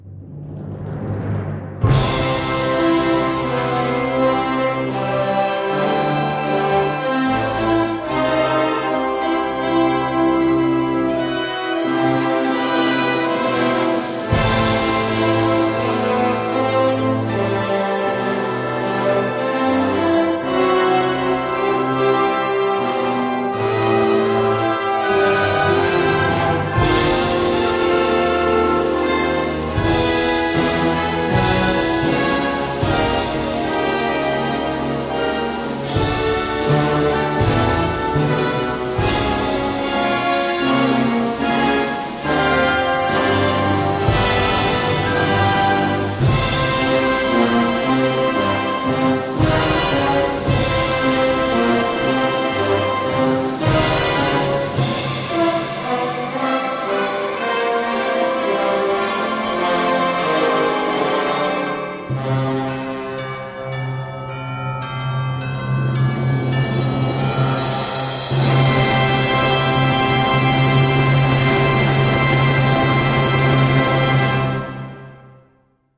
Gattung: Dokumentation in 4 Sätzen
Besetzung: Blasorchester